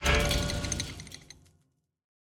Minecraft Version Minecraft Version snapshot Latest Release | Latest Snapshot snapshot / assets / minecraft / sounds / block / trial_spawner / detect_player3.ogg Compare With Compare With Latest Release | Latest Snapshot